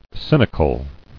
[cyn·i·cal]